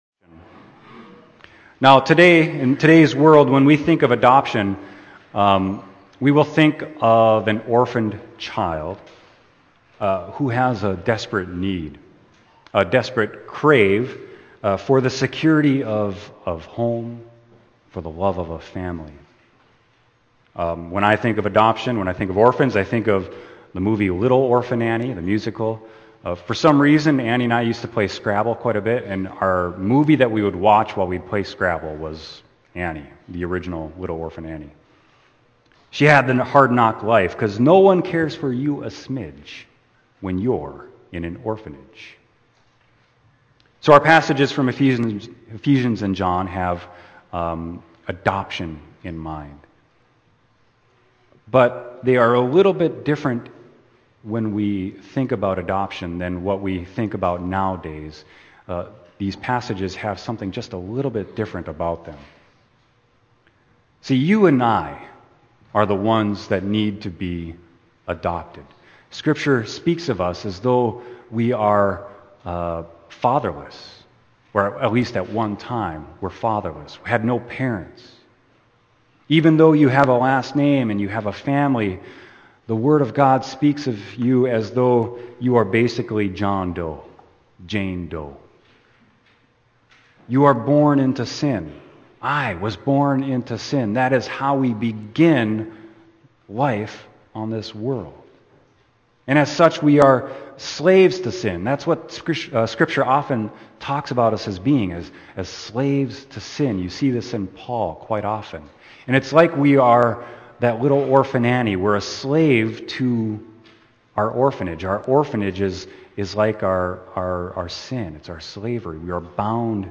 Scriptures: Ephesians 1:3-14; John 1:1-18 Sermon: John 1.10-18